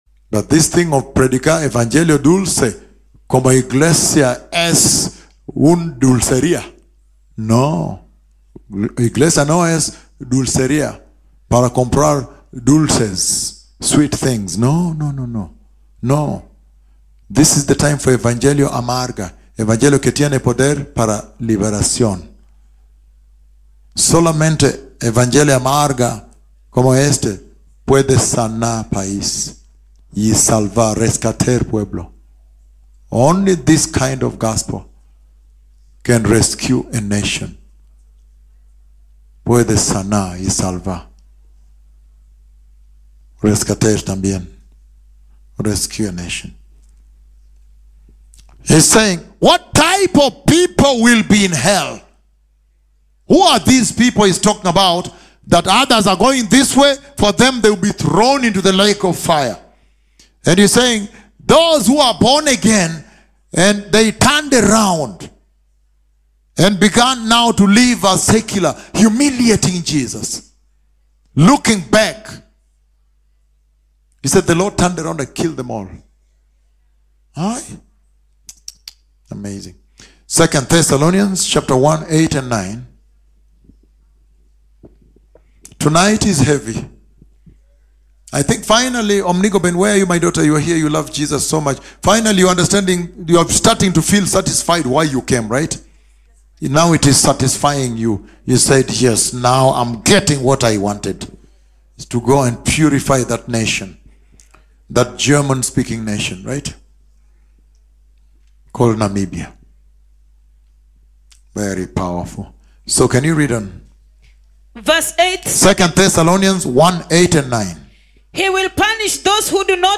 Extrait - La Conférence du Discipolat (Jour 2) depuis Nairobi au Kenya, présentée par Les Puissants Prophètes de Jéhovah.